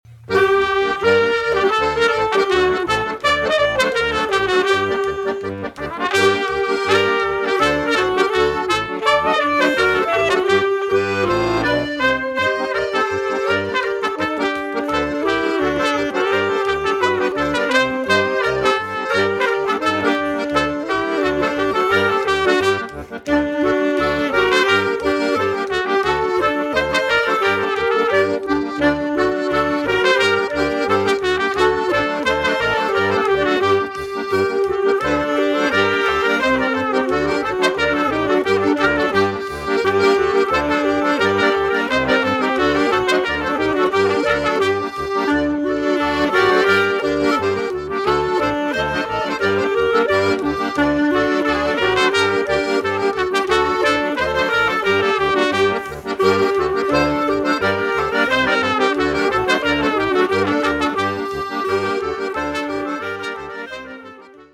Bassgitarren